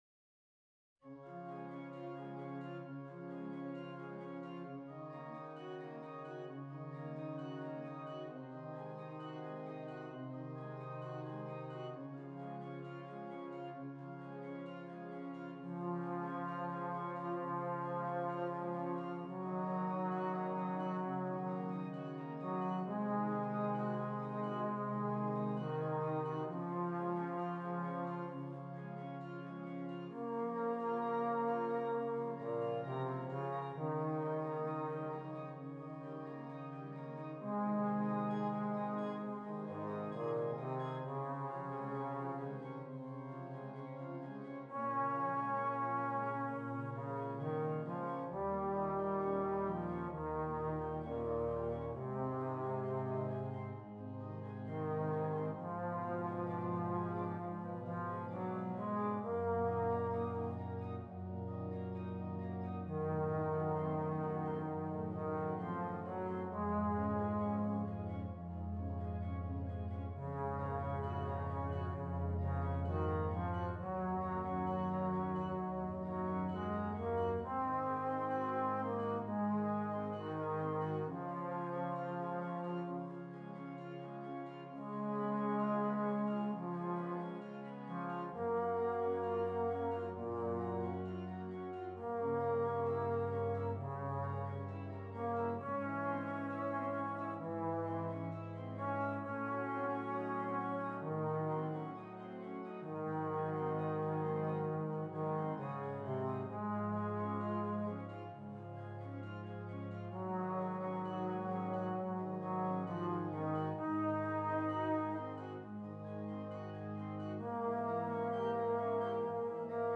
Trombone and Keyboard